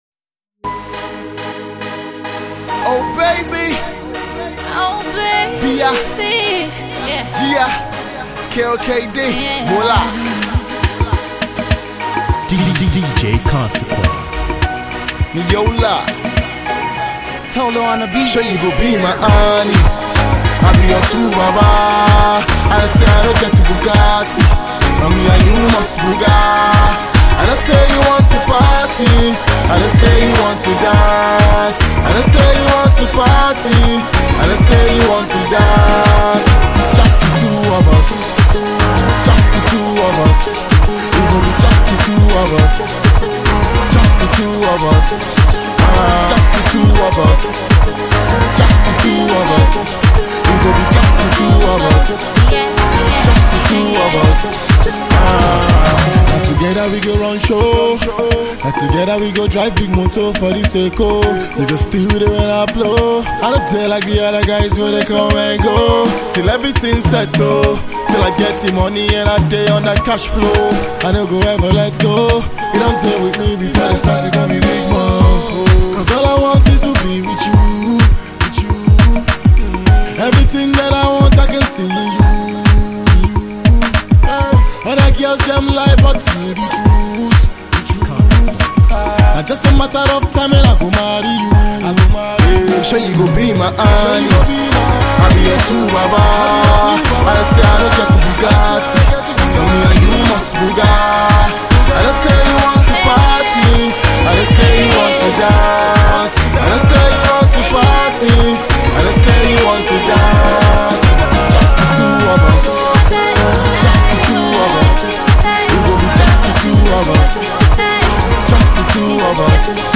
a catchy love song